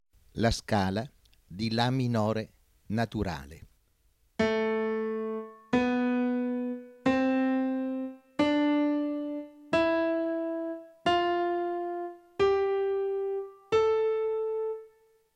06. Ascolto della scala di La minore naturale.